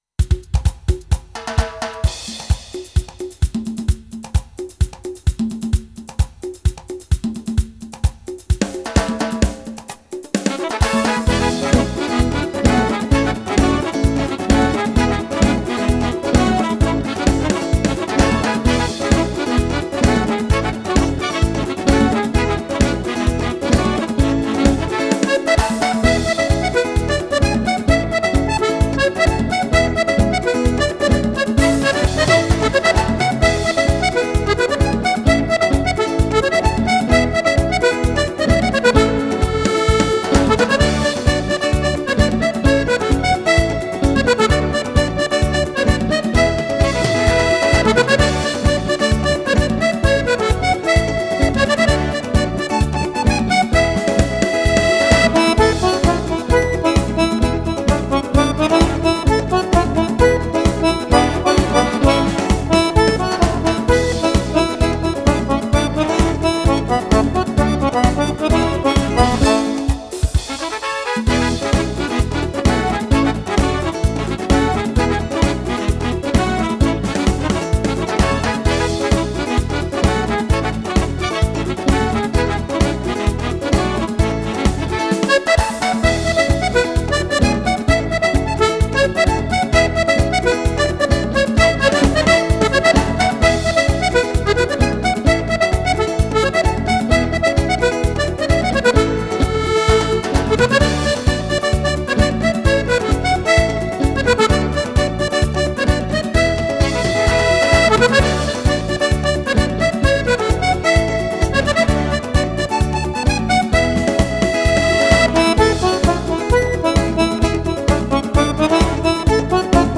Merengue
ballabili per sax e orchestra stile Romagnolo.